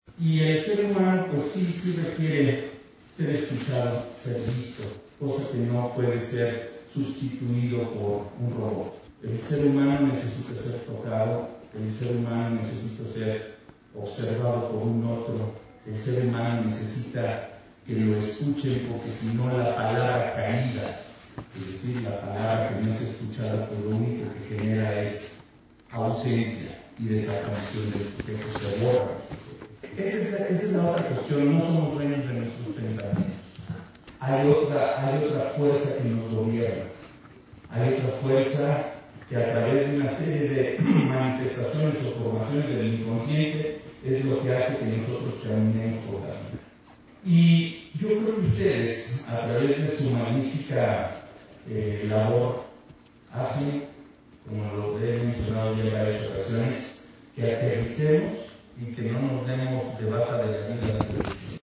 En el mensaje inaugural, el titular de la Secretaría de Salud de Hidalgo (SSH), Alejandro Efraín Benítez Herrera, destacó que el papel del profesional de Psicología es fundamental en la sociedad para brindar una atención integral a las personas, ya que, proporciona psicoterapia y orientación en una gran variedad de situaciones.
Secretario-de-Salud-de-Hidalgo-Alejandro-Efrain-Benitez-Herrera.mp3